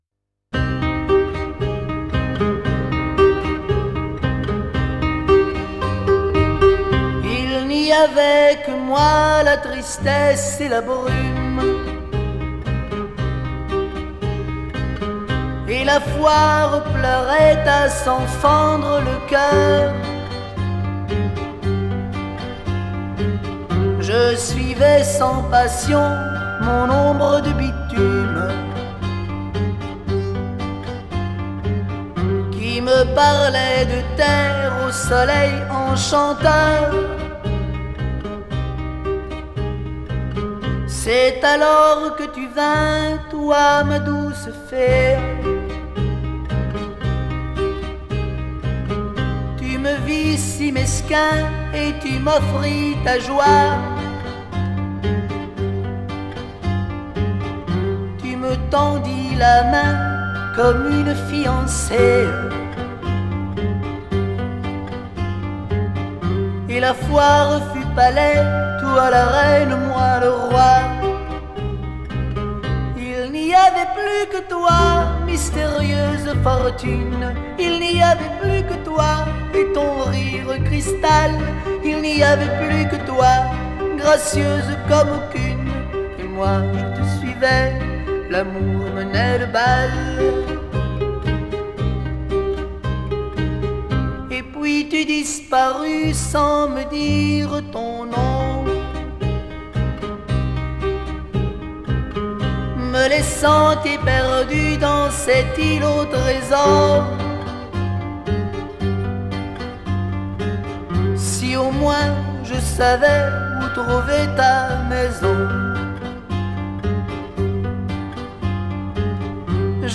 Genre: Chanson, French Pop